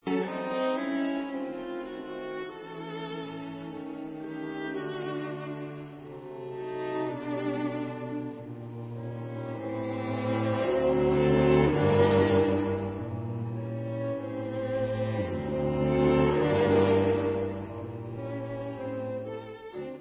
Adagio, ma non troppo